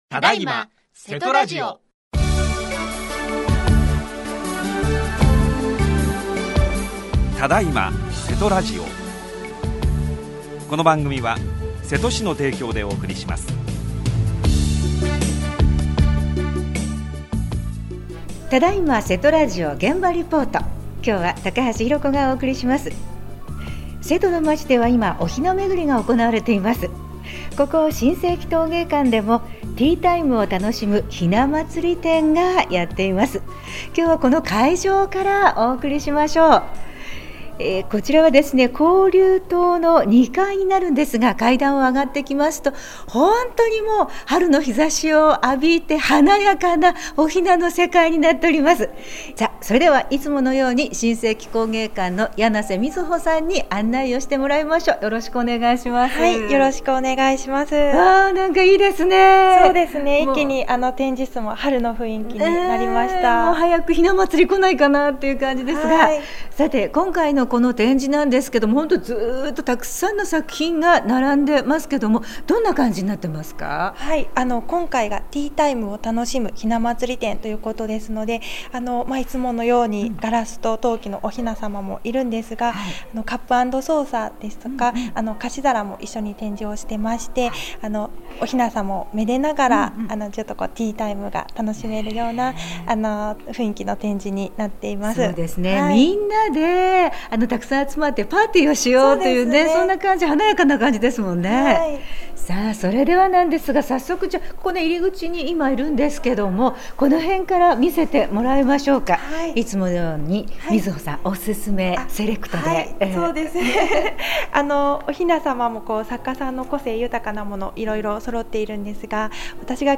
今回は、現場レポートでお送りしました。